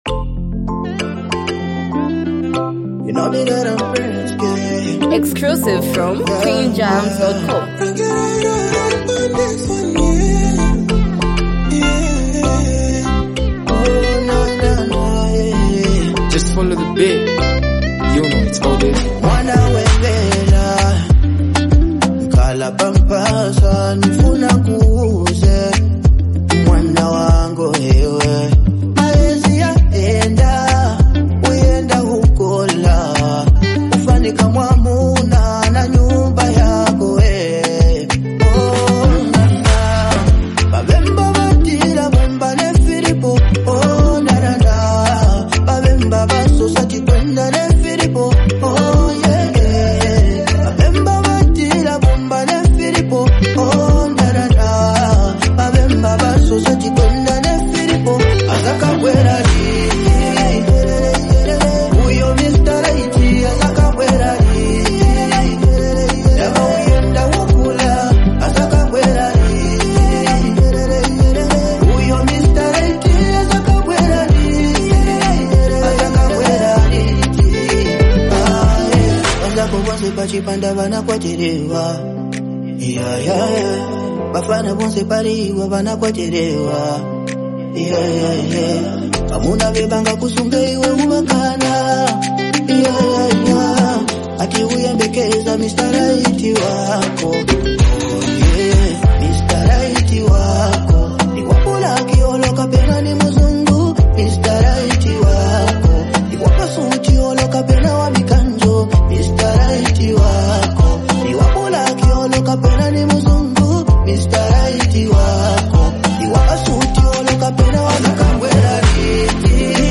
romantic song
smooth and melodic contribution